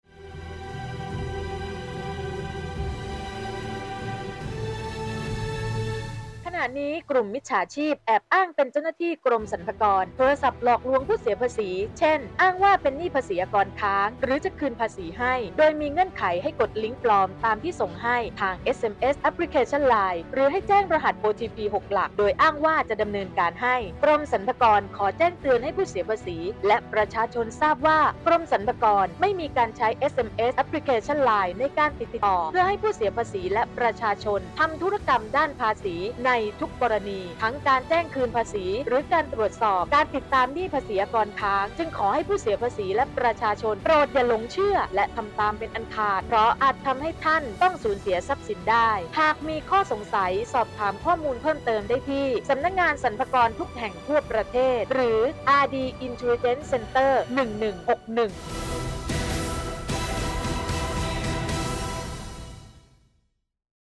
1.สารคดีสั้น ความยาว 1 นาที